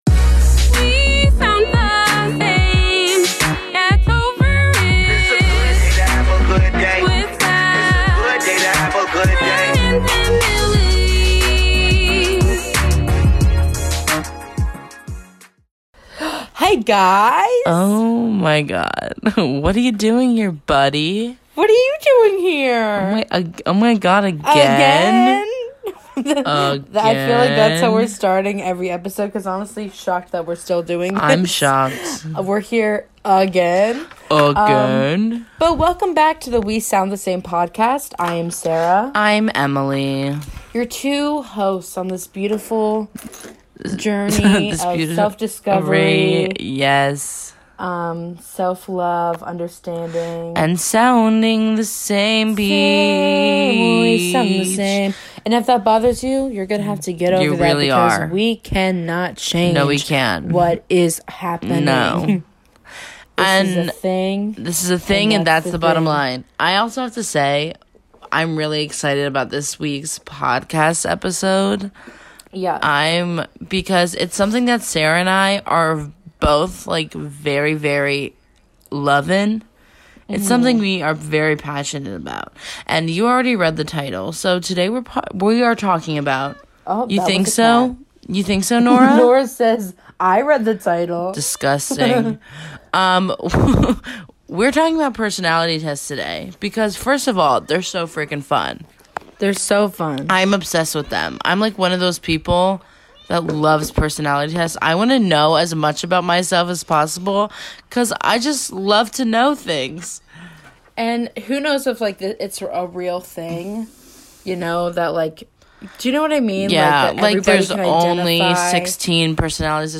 Play Rate Listened List Bookmark Get this podcast via API From The Podcast Just two twin sister's who love to hear themselves talk, giggling and chatting about anything and everything Join Podchaser to...